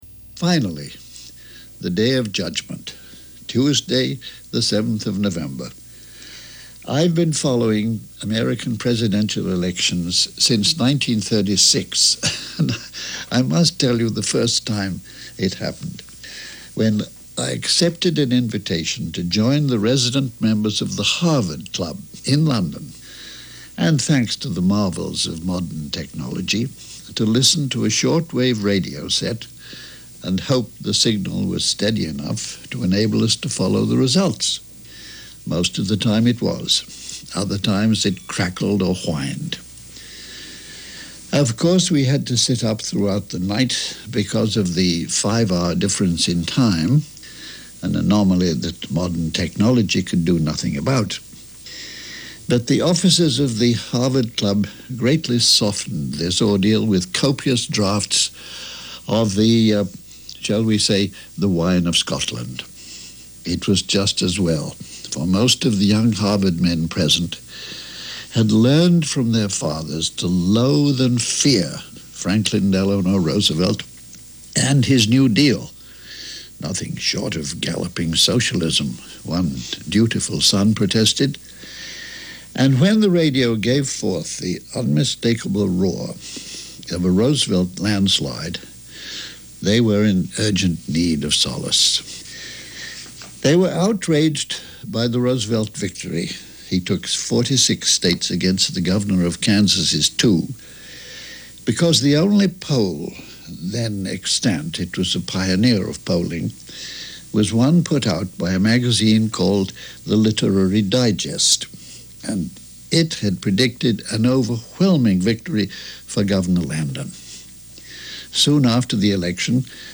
This edition of Alistair Cooke’s Letter From America came from one of those relays to a College Radio station who gave over their overnight hours to running BBC broadcasts.